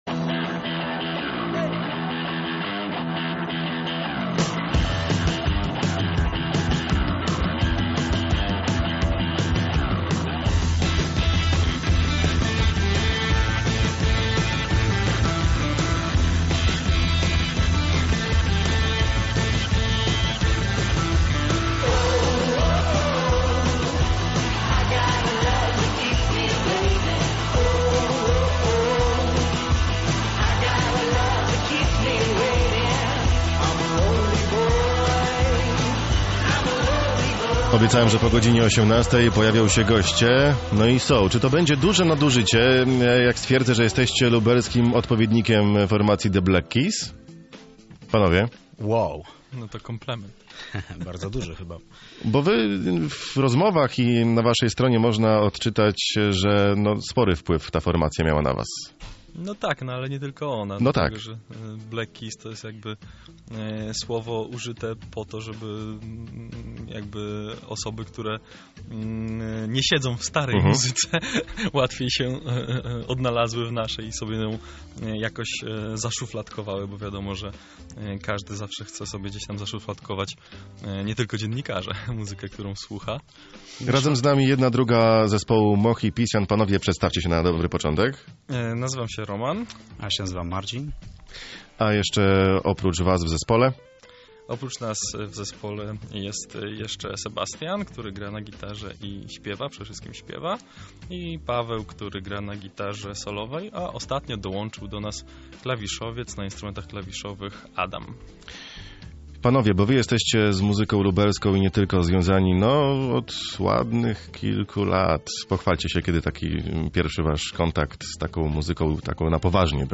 Mohipisian-wywiad-Radio-Centrum.mp3